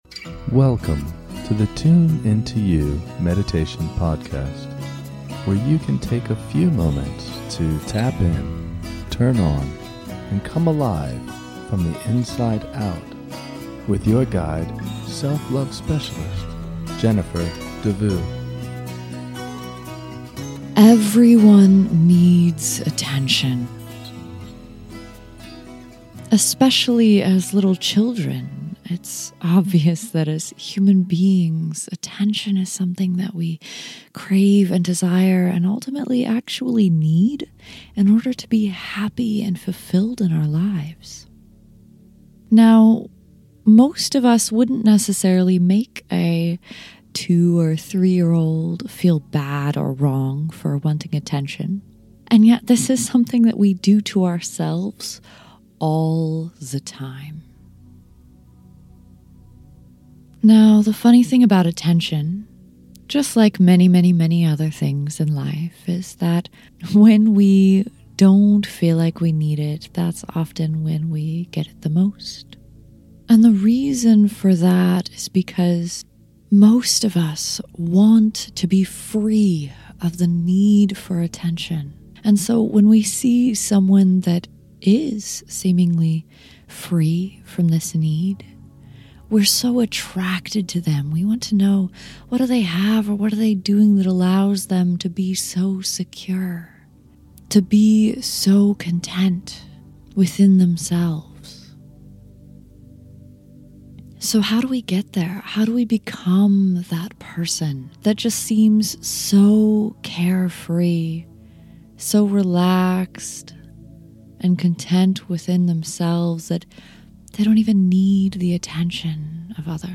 In this short guided meditation, we will explore the need for attention and validation from others and find our inherent inner security.